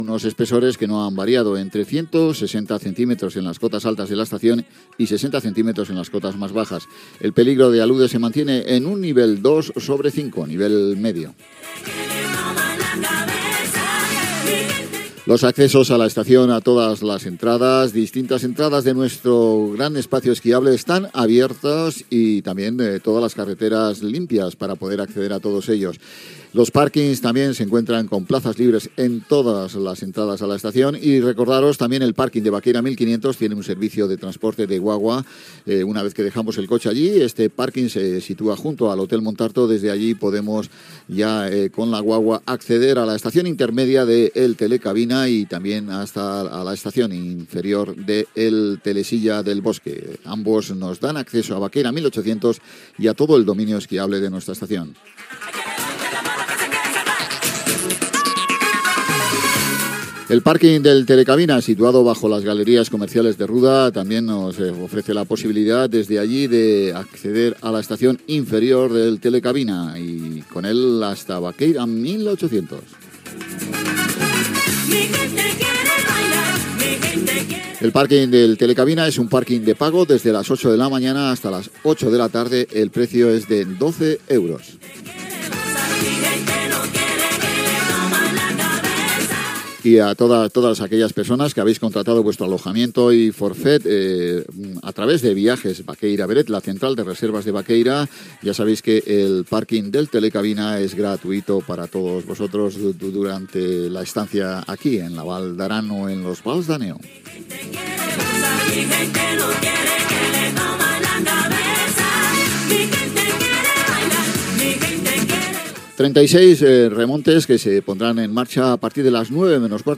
Estat de les pistes d'esquí i dels accessos, horari de la Lluna, nova aplicació de Baqueira per a telefonia mòbil, tema musical, indicatiu, previsió meteorològica, tema musical, pistes i serveis de Baqueira Beret Gènere radiofònic Info-entreteniment